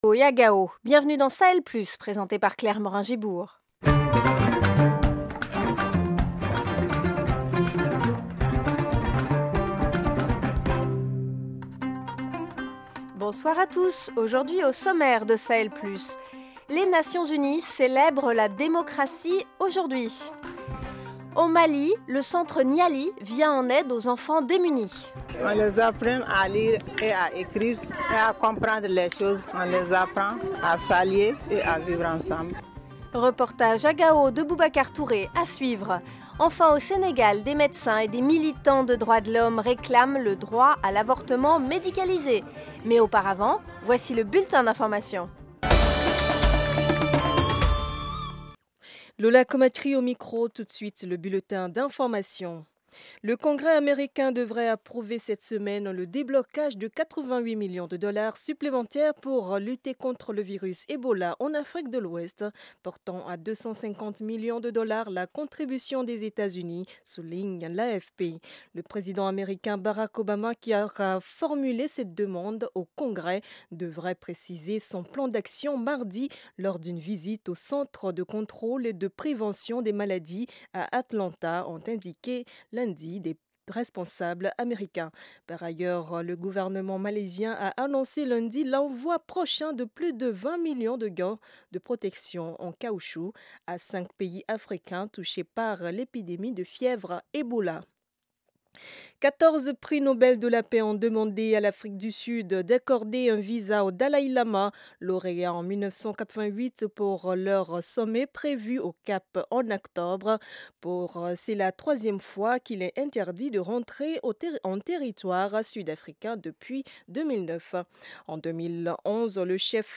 Au programme : Journée internationale de la démocratie : comment promouvoir l’engagement des jeunes en politique ? Le Centre Niali à Gao au Mali vient en aide aux enfants démunis. Reportage